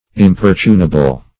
Importunable \Im*por"tu*na*ble\, a. Heavy; insupportable.